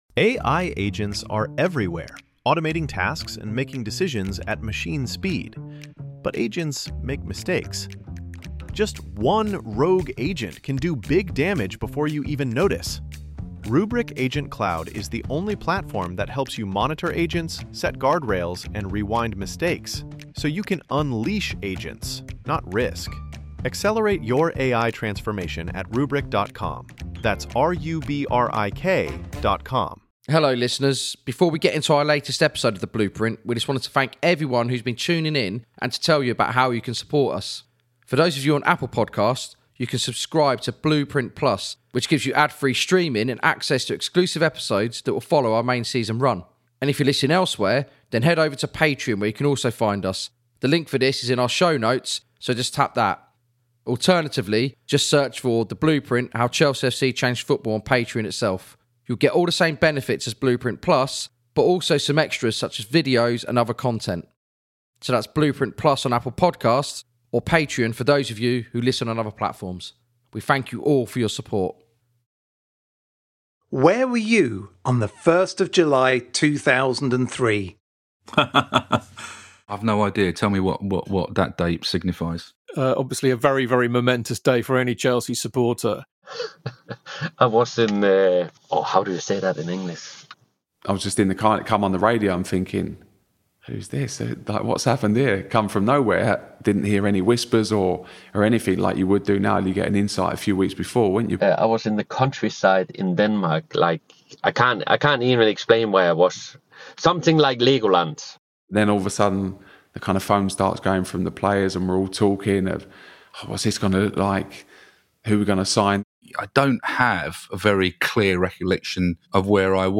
Archive used in this episode is courtesy of Chelsea TV.